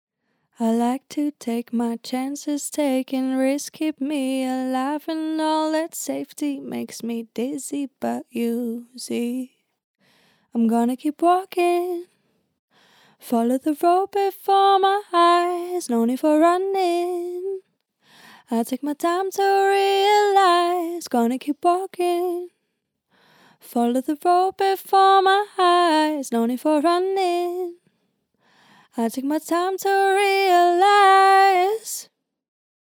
Condenser, externally polarized
Cardioid
ray_fem-vox_mix-18lufs_k1.mp3